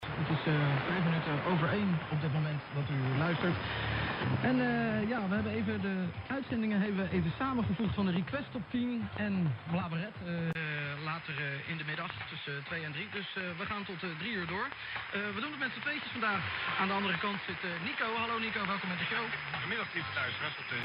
Leider hat der R75 einen Basslastigen, dumpfen Klang.
In SSB mag dies noch gehen, aber in der Betriebsart AM klingt das ganze ziemlich dumpf.
Hier zwei Audiobeispiele vom Icom IC-R75, mit und ohne Equalizer.
Sekunde 0-10 ohne EQ
Sekunde 10-20 mit EQ
Icom IC-R75 Audio-6005khz-AM